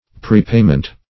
Search Result for " prepayment" : Wordnet 3.0 NOUN (1) 1. payment in advance ; The Collaborative International Dictionary of English v.0.48: Prepayment \Pre*pay"ment\, n. Payment in advance.